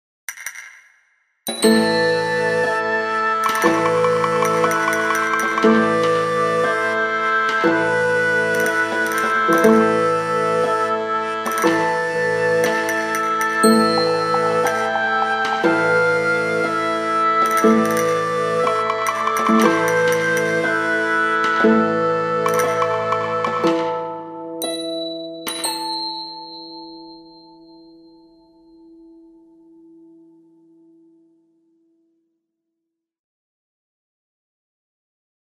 Musik und Klangcollagen für ein Freilichttheaterprojekt
Auf akustischer Ebene entstanden für dieses Projekt verschiedene Musikstücke und Geräuschcollagen, die die einzelnen Spielszenen miteinander verbanden, gleichzeitig atmosphärisch verdichteten und somit den Bühnenraum erweiterten.